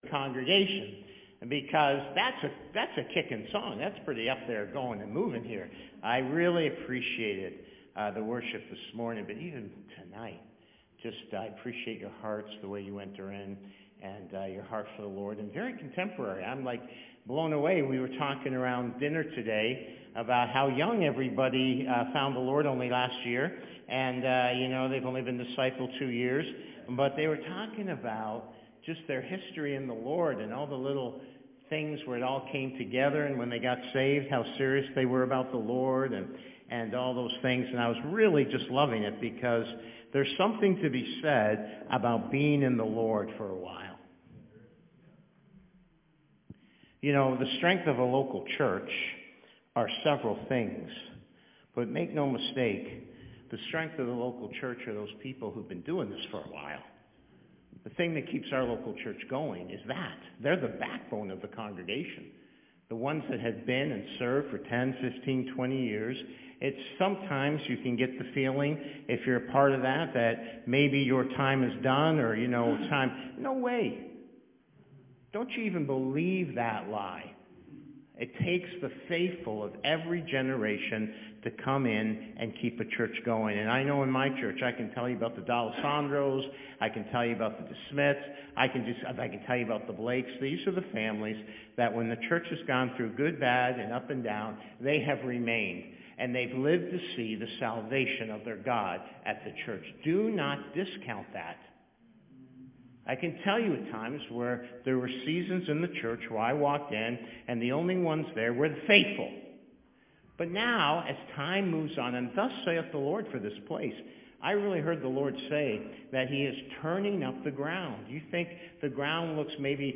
Special Evening Service: Part 1 - A prophetic word for congregation members (~ 30 minutes) Part 2 - Misunderstandings of the Prophetic (~ 30 minutes)